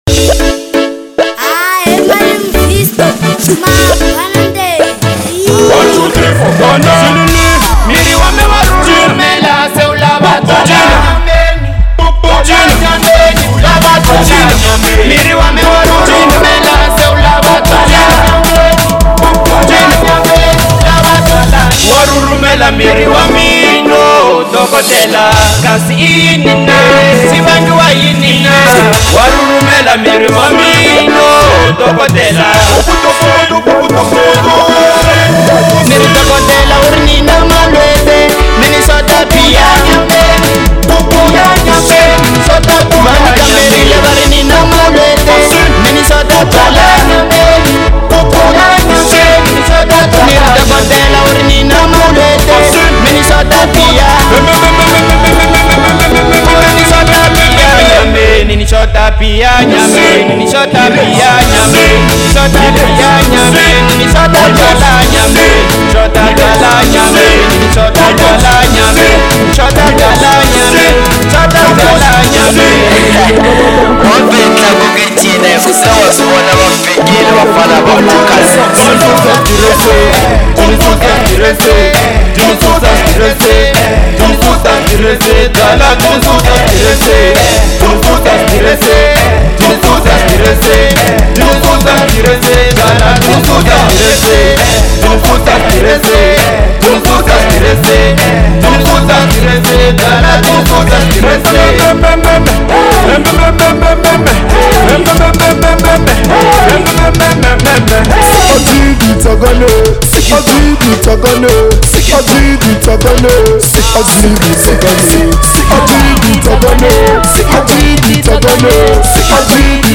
Genre : Local House